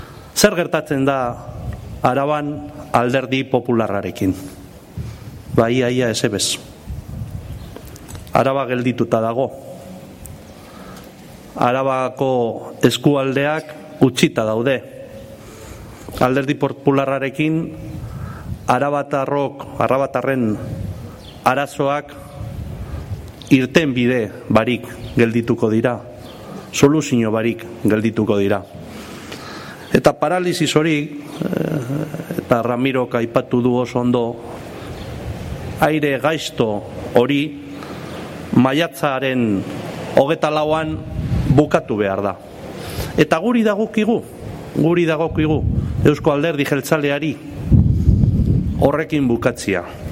Andoni Ortuzar en la presentación de las candidaturas municipales de Rioja Alavesa (1)